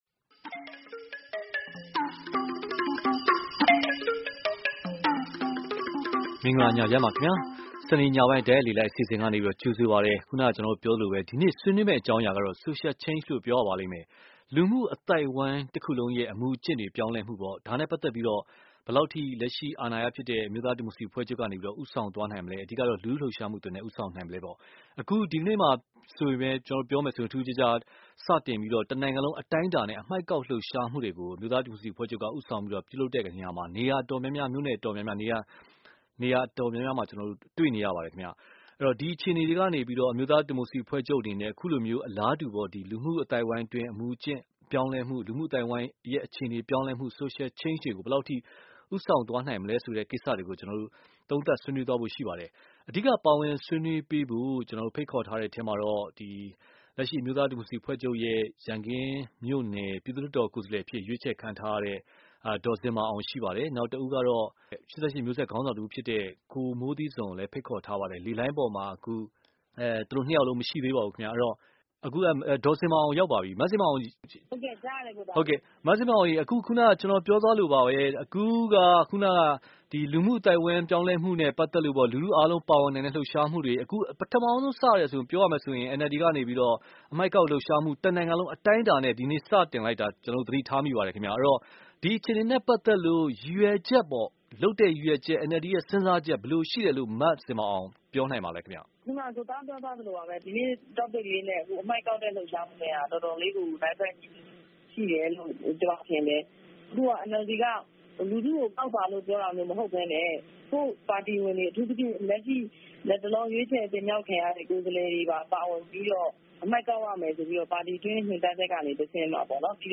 စနေနေ့ညတိုက်ရိုက်လေလှိုင်းအစီအစဉ်